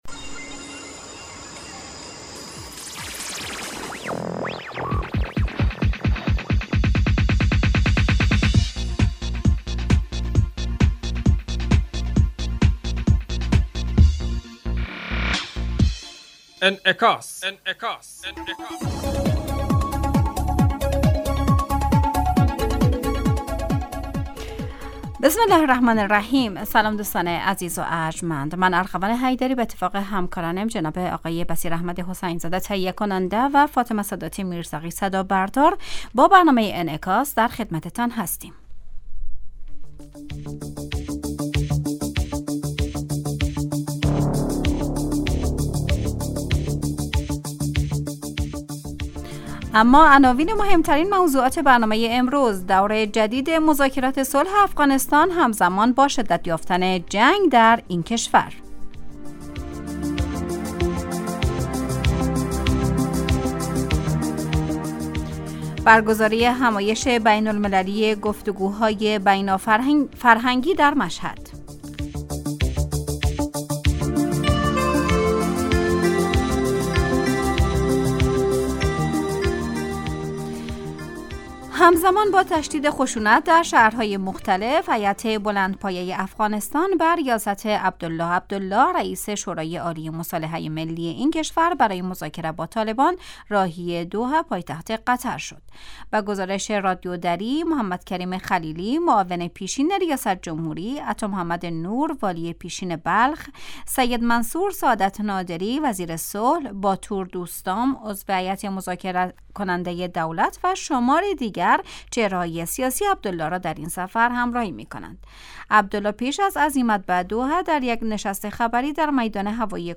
برنامه انعکاس به مدت 30 دقیقه هر روز در ساعت 12:10 ظهر (به وقت افغانستان) بصورت زنده پخش می شود.